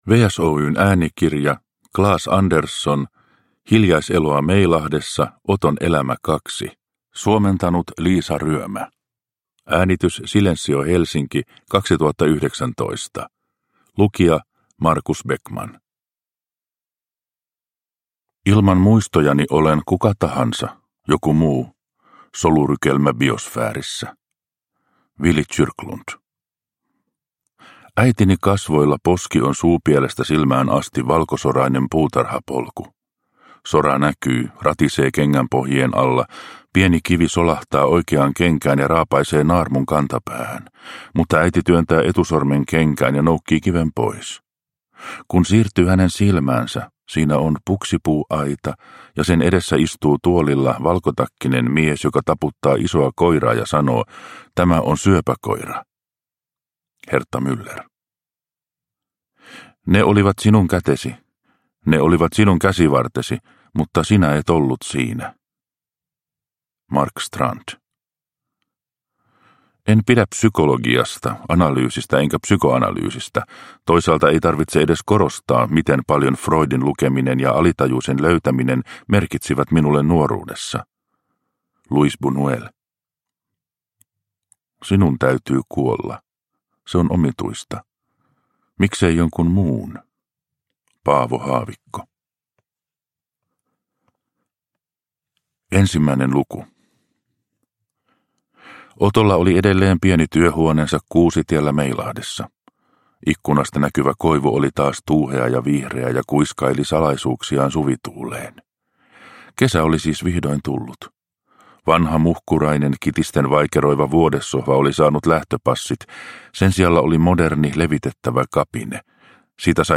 Hiljaiseloa Meilahdessa – Ljudbok – Laddas ner